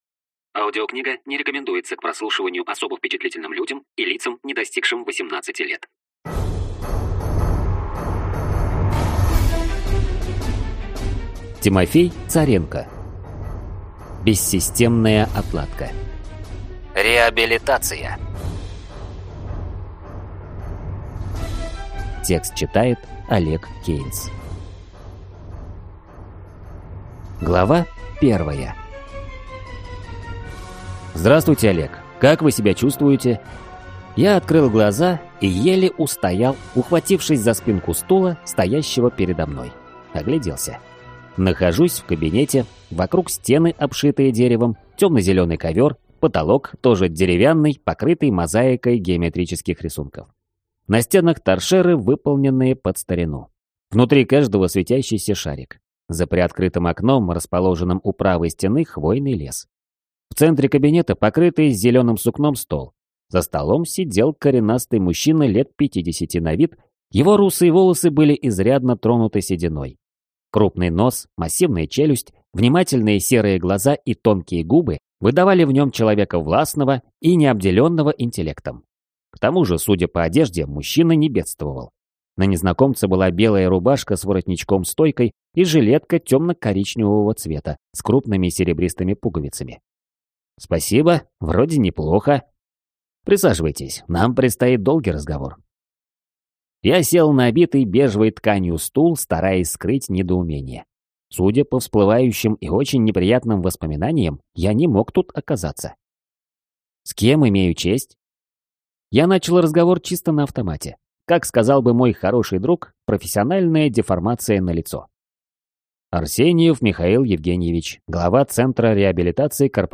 Аудиокнига Бессистемная отладка. Реабилитация | Библиотека аудиокниг